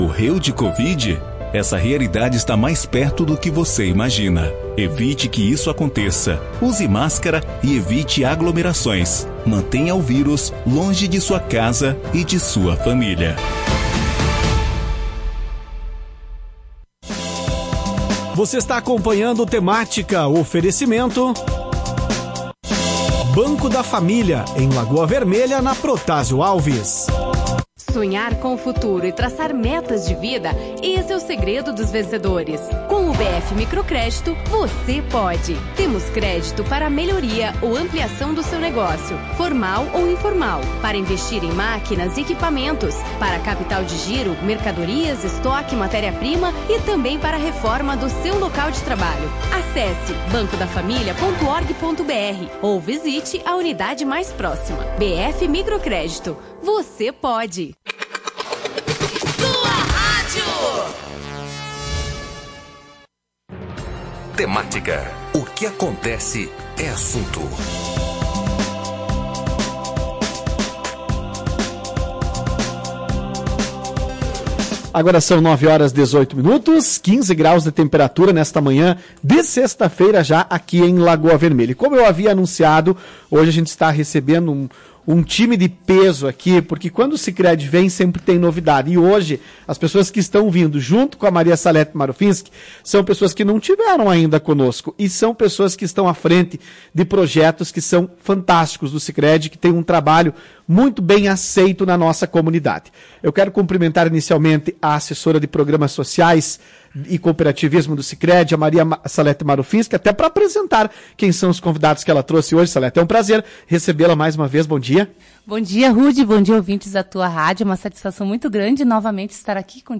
Ouça a entrevista e saiba mais sobre a busca pelo protagonismo do jovem no cooperativismo, fomentado pelo programa A União Faz a Vida.